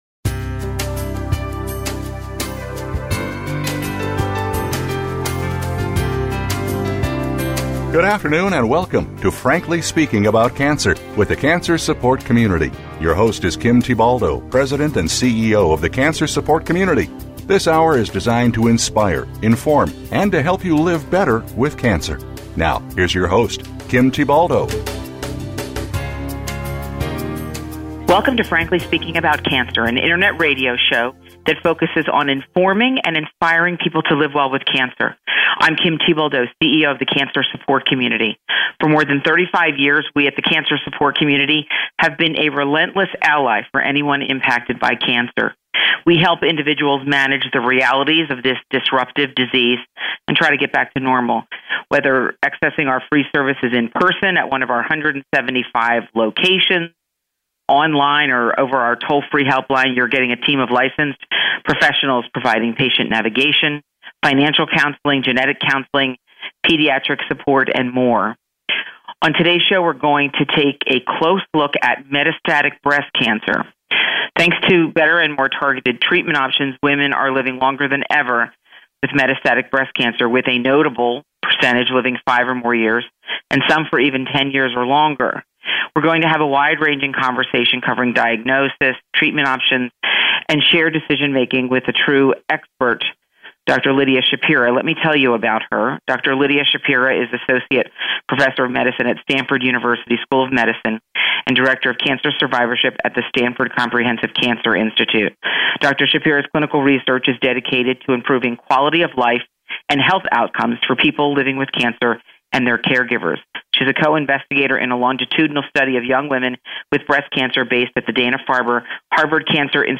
Thanks to better and more targeted treatment options, women are living longer than ever with metastatic breast cancer. This episode features a wide-ranging conversation covering diagnosis, treatment options and shared decision making.